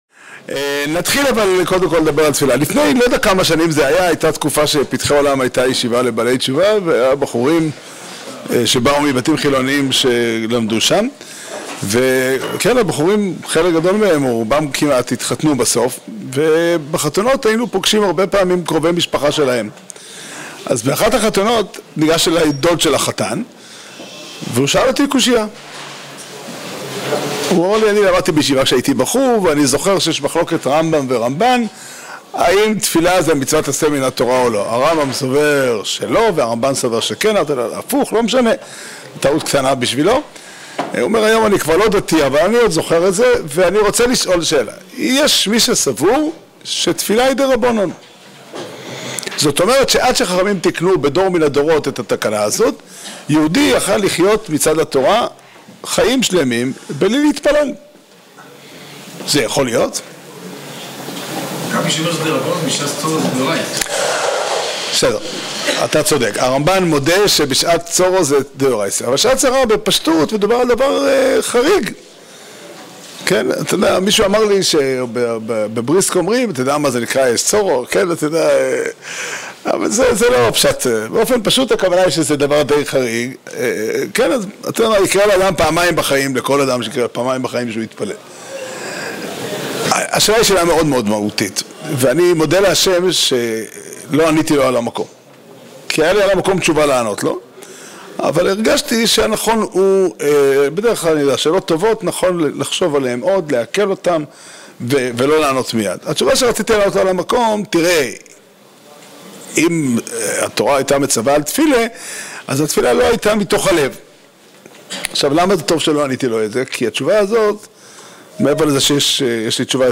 השיעור נמסר במסגרת הלימוד השנתי בליל הו"ר תשפ"ה בסוכה בהר נוף